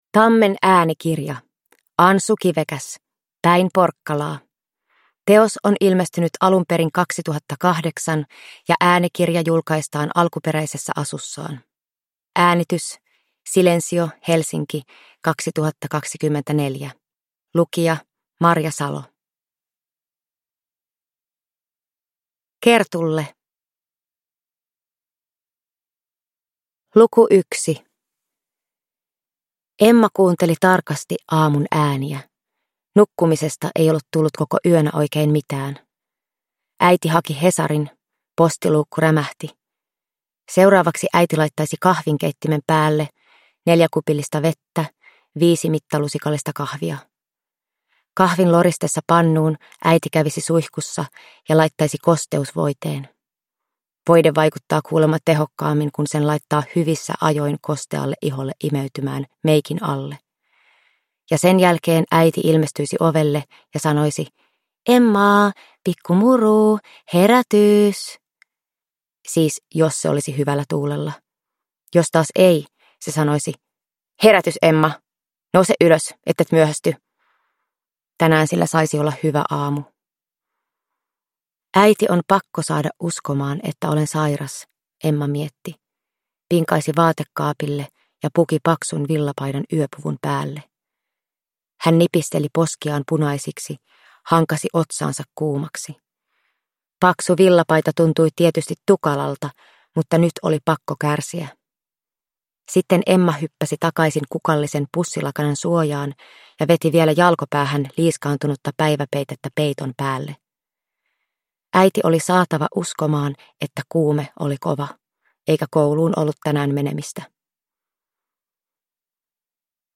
Päin Porkkalaa – Ljudbok
Tarina on myös kielellisesti mehevä, kun kirjakielen lomassa vaihtelevat koululaisten puhekieli, ruotsin kieli ja karjalaismurre.